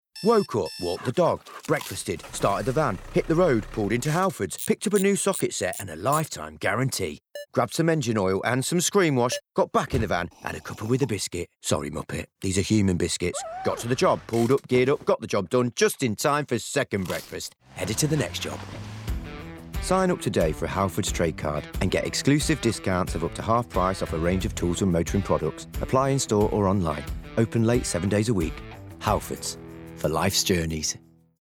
30/40's Light Midlands/US,
Comedic/Expressive/Versatile